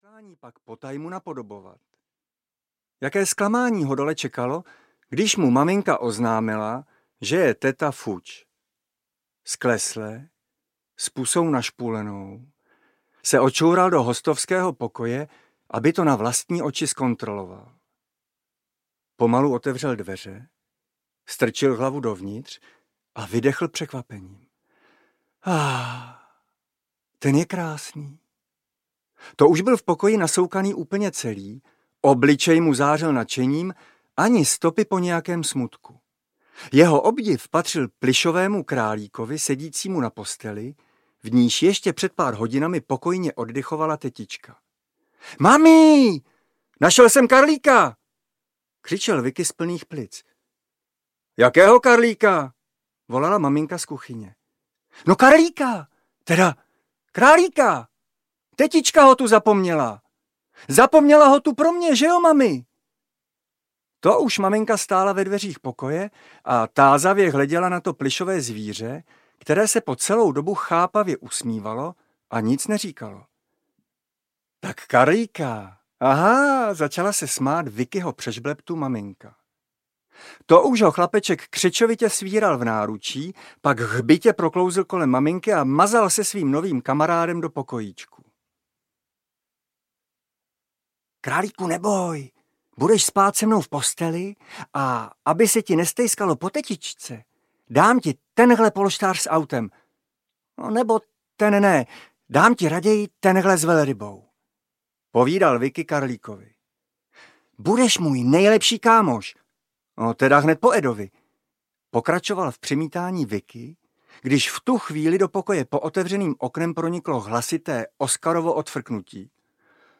Králík Karlík audiokniha
Ukázka z knihy